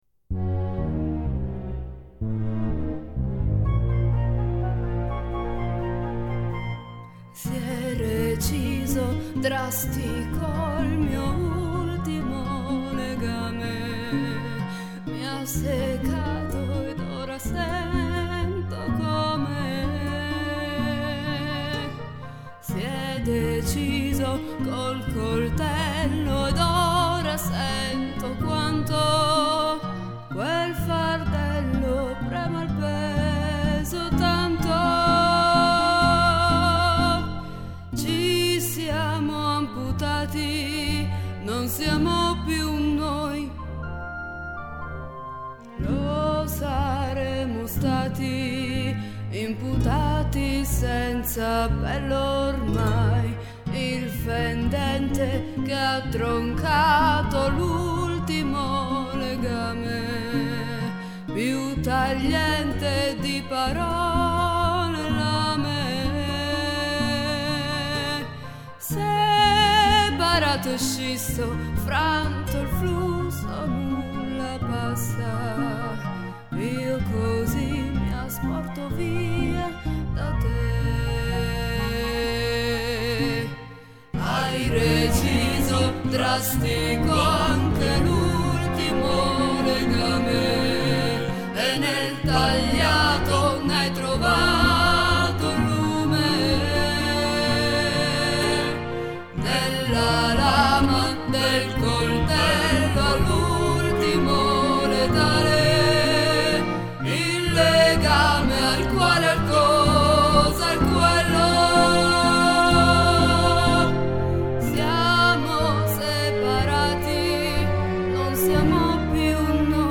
Melologo e pantomima musicale